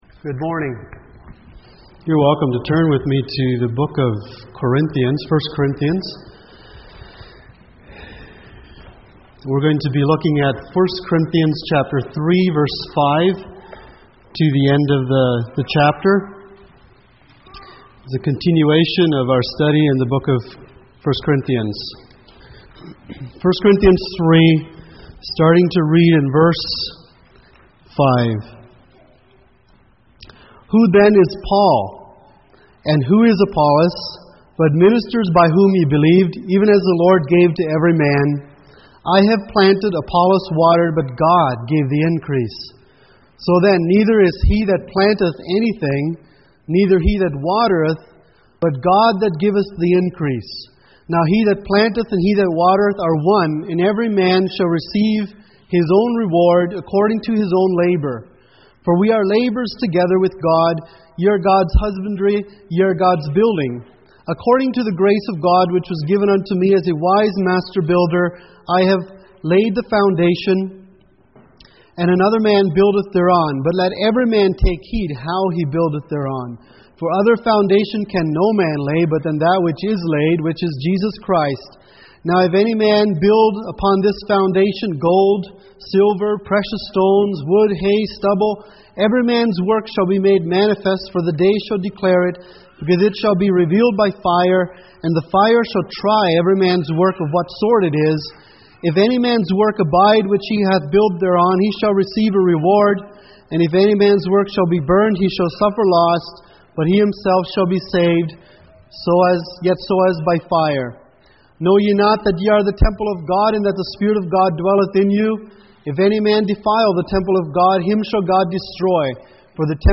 Expostition of 1 Corinthians Passage: 1 Corinthians 3:5-23 Service Type: Sunday Morning %todo_render% « Does Your Christianity Look Like Jesus?